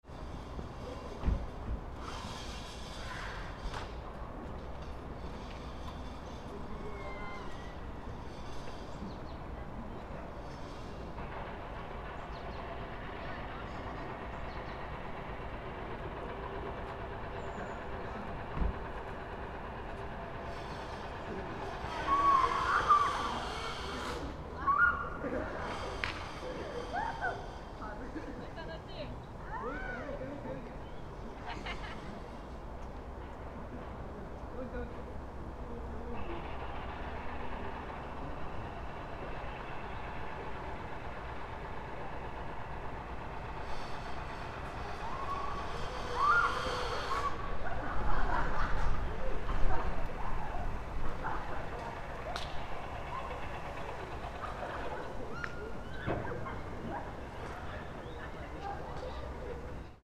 On the day 14 years after the quake, some high school students were playing in the park cheerfully. ♦ Occasionally, some birds were twittering. ♦ The sounds from construction sites around the park were resounding.